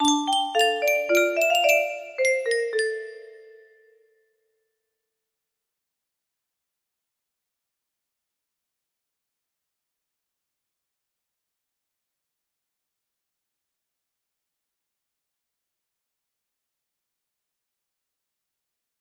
BPM 110